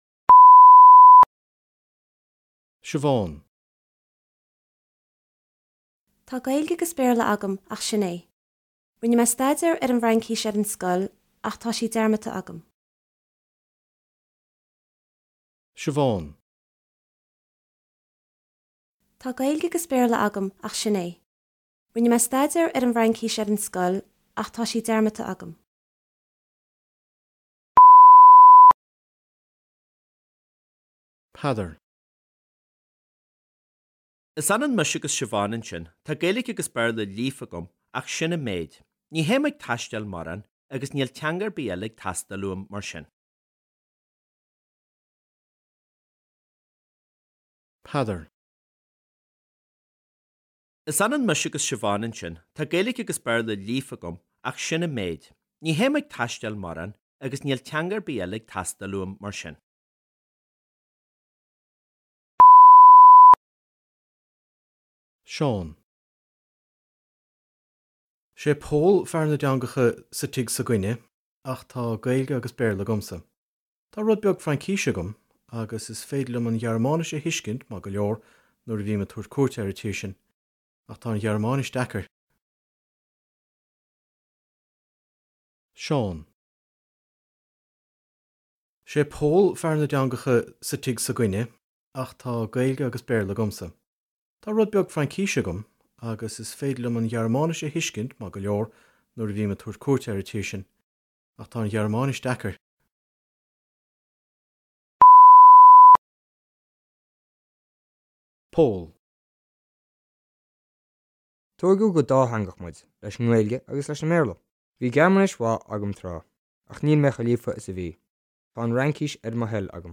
Scil: Cluas
Sna bileoga oibre seo, beidh na foghlaimeoirí ag éisteacht le daoine ag labhairt faoina gcumas i dteangacha eile, agus faoi na dúshláin a bhaineann le foghlaim teanga nua.